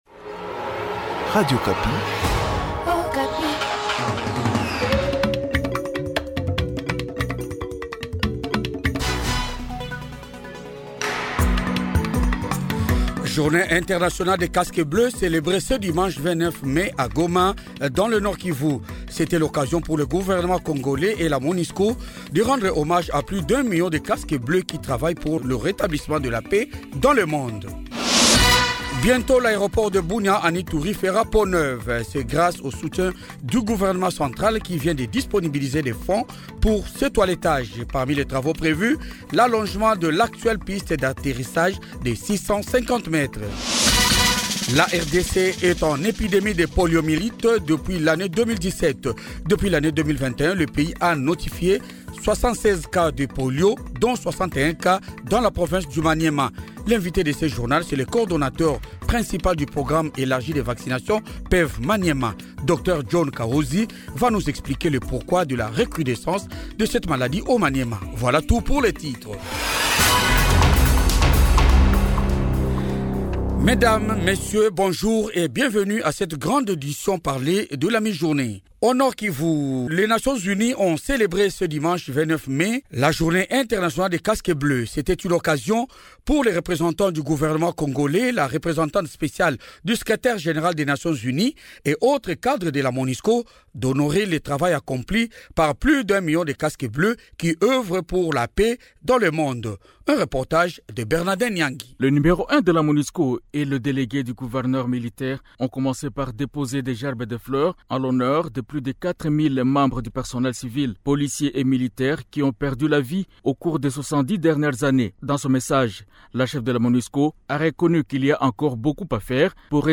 Titres du journal de 12 heures du 29 mai 2022